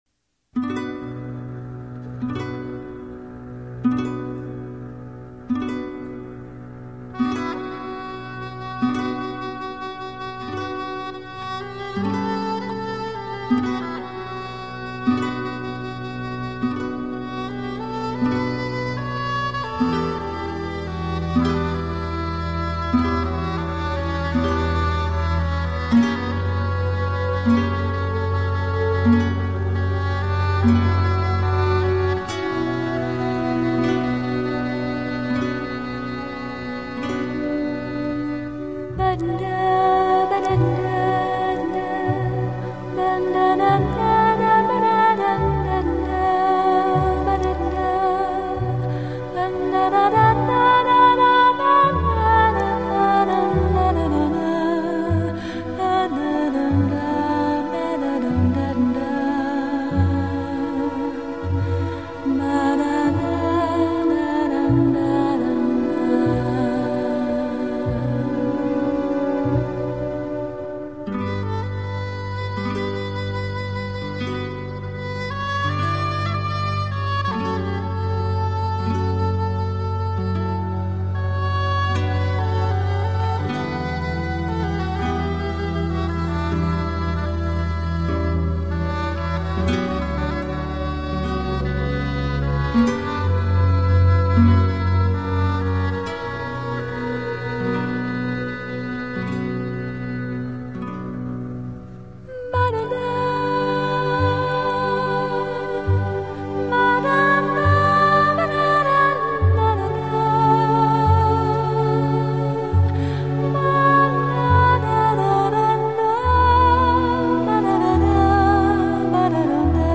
第二乐章的音乐是吉他史上最令人心醉神驰的旋律。
而曲中的女声和声，将神秘、空灵、孤寂的情绪完美地结合在一起，使听者仿佛穿过时空，置身于遥远的西班牙。